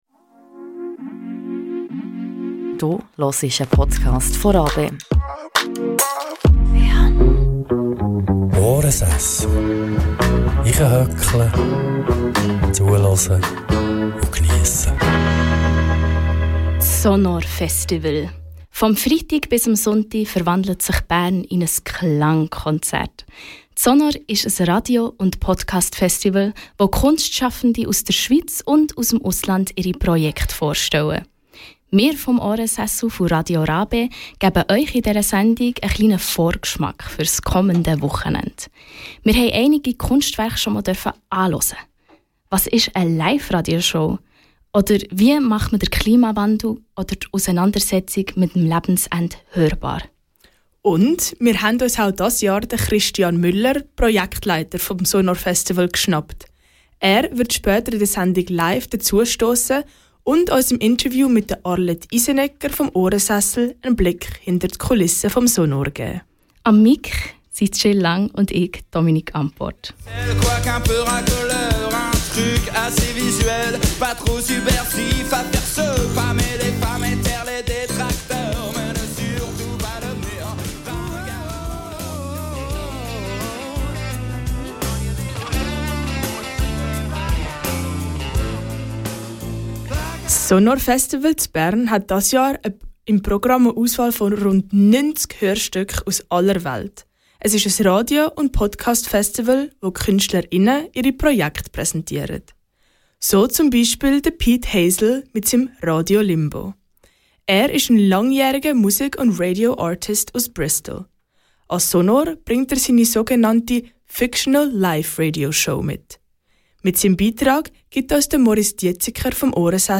Zwei Sendunsmacher:innen von Radio RaBe haben den Klimawandel mit dem Mikrofon eingefangen - nicht irgendeinem, sondern ein paar ganz spezielle, erzählen die beiden in dieser Episode.
In diesen Episoden hörst du den Zusammenschnitt der live-Sendung.